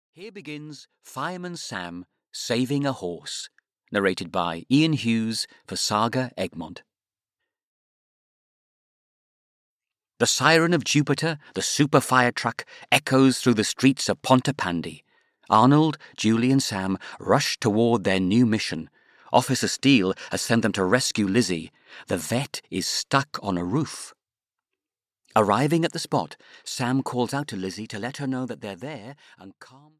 Audio knihaFireman Sam - Saving a Horse (EN)
Ukázka z knihy